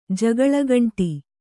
♪ jagaḷagaṇṭi